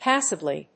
音節páss・a・bly 発音記号・読み方
/‐səbli(米国英語), ˈpæsʌbli:(英国英語)/